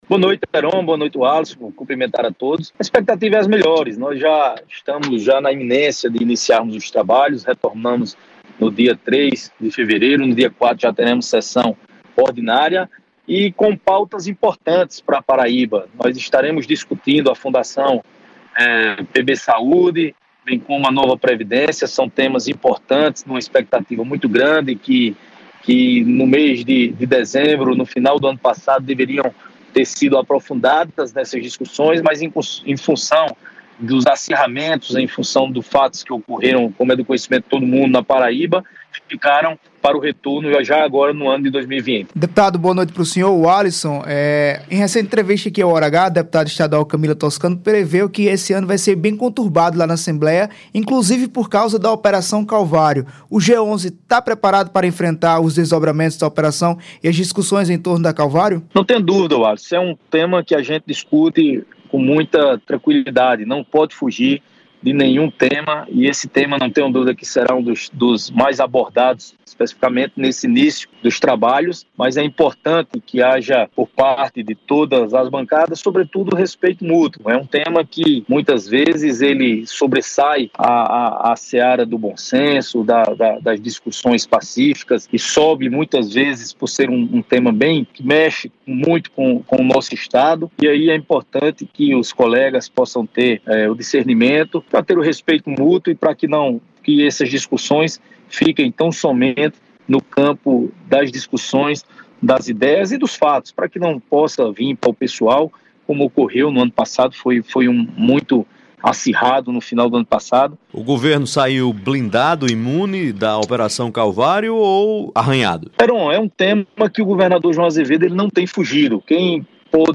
Em entrevista ao Hora H, programa da Rede Mais, o parlamentar avaliou que João não em fugido do tema, nem das indagações sobre seu envolvimento nos supostos casos de corrupção.